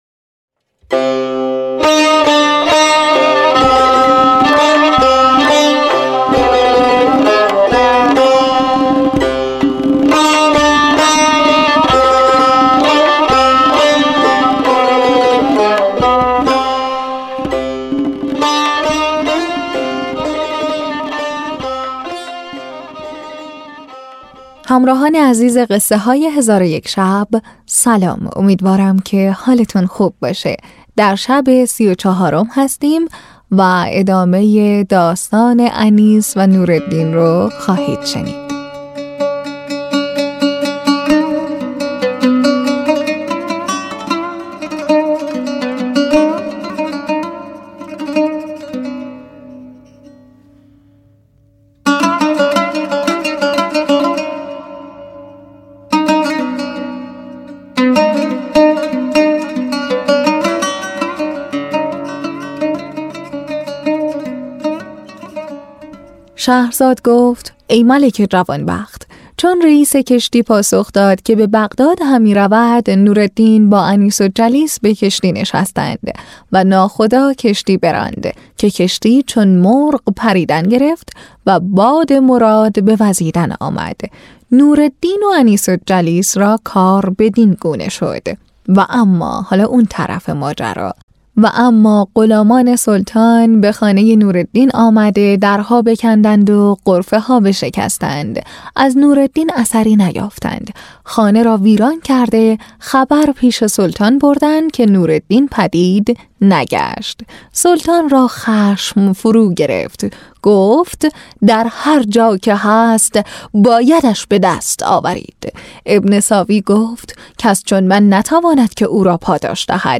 تهیه شده در استودیو نت به نت